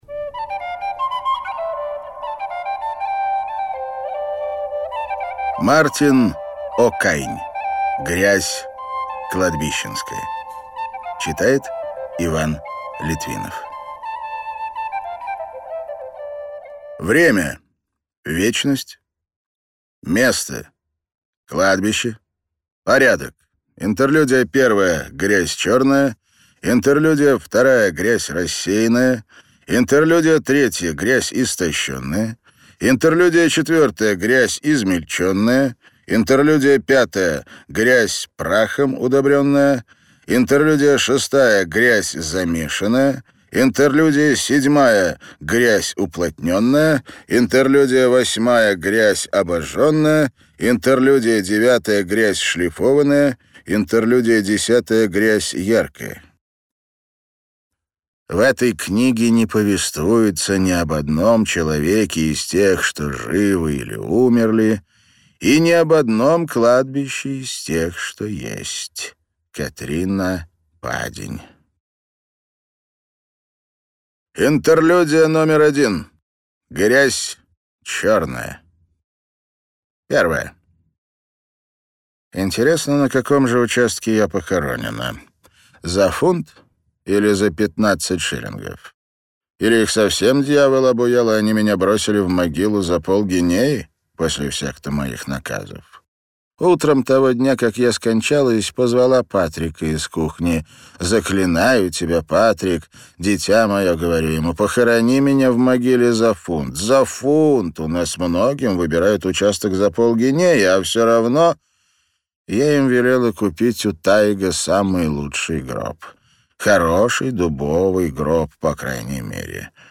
Аудиокнига Грязь кладбищенская | Библиотека аудиокниг
Прослушать и бесплатно скачать фрагмент аудиокниги